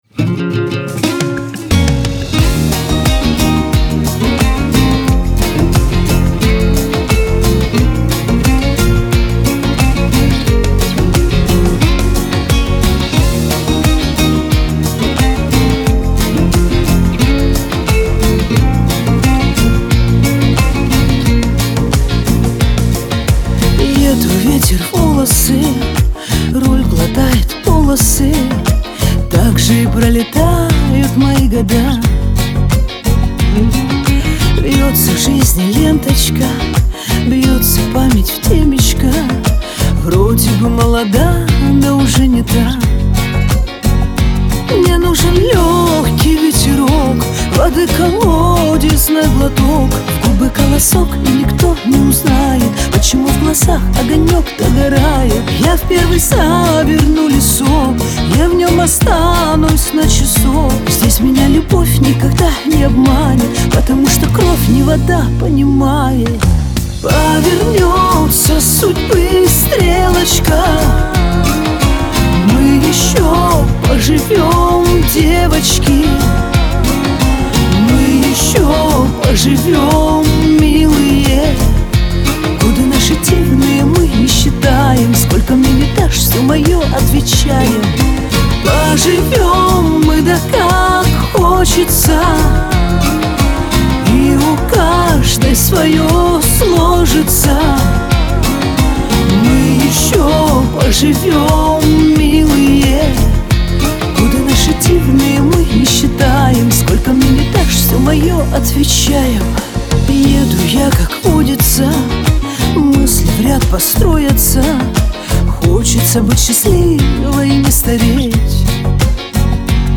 pop
Лирика